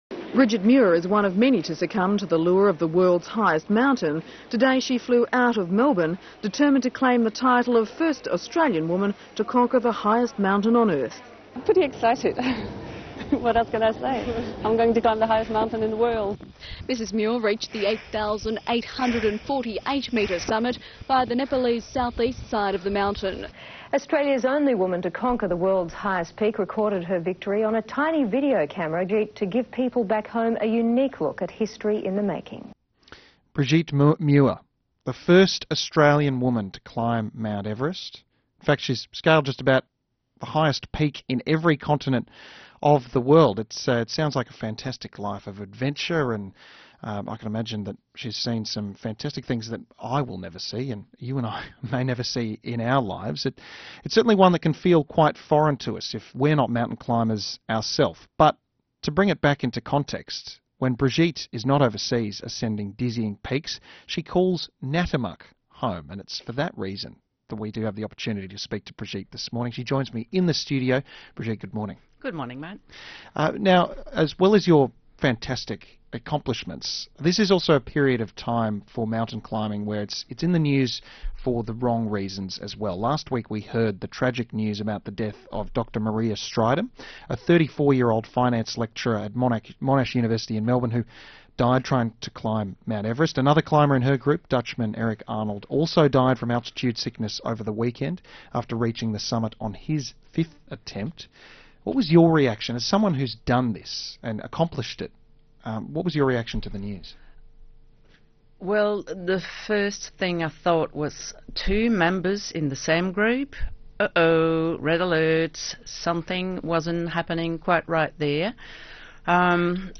Everest Radio Interview, ABC Western Victoria